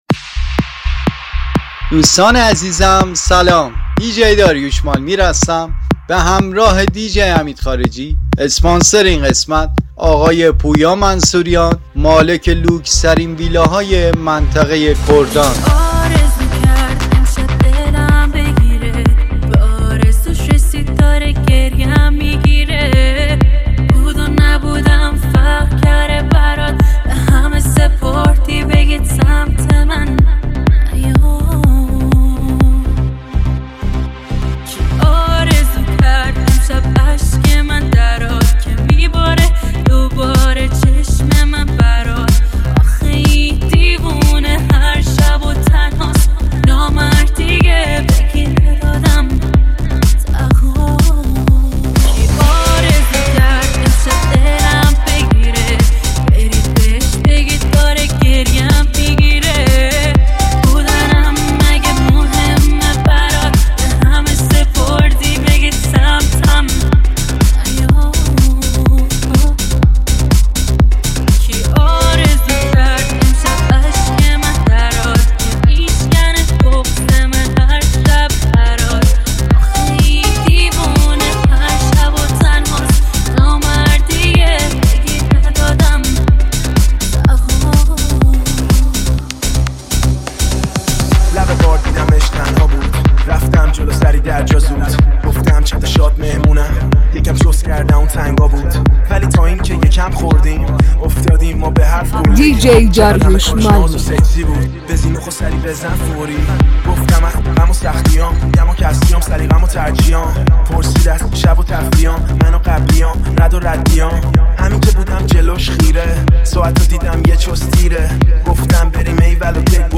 ریمیکس باشگاهی